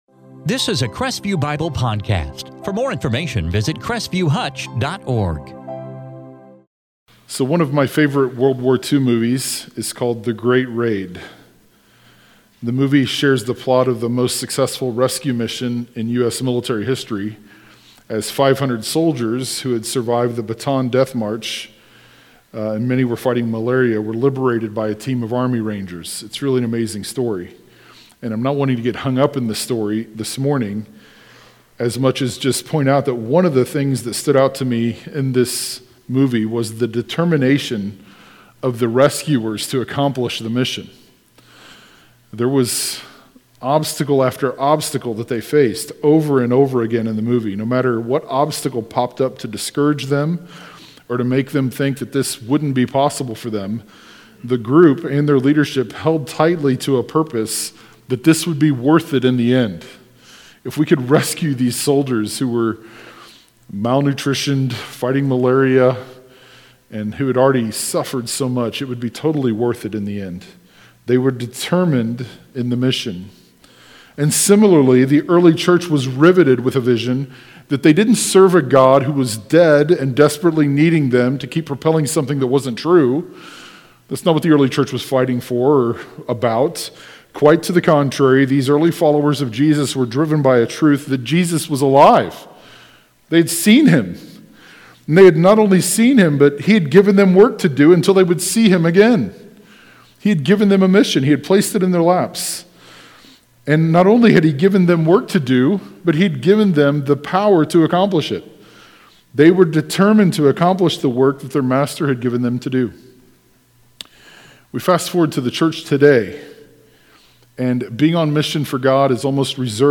In this sermon from Acts 21-22